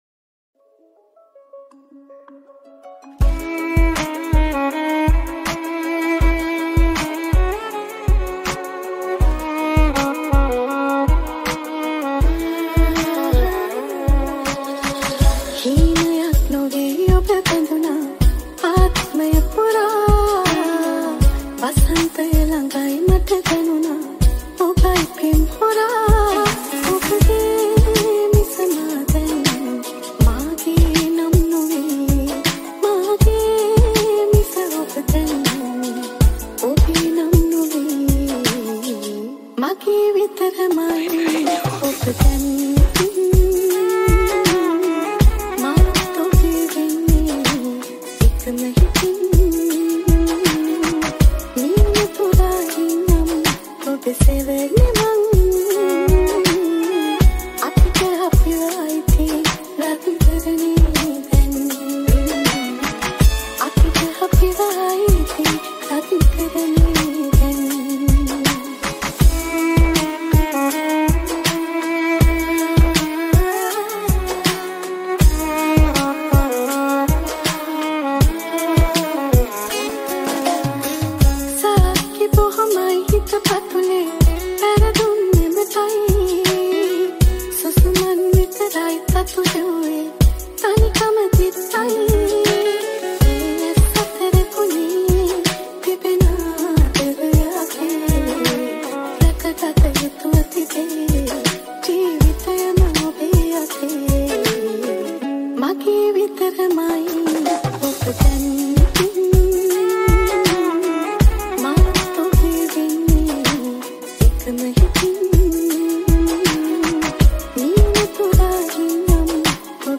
80 Bpm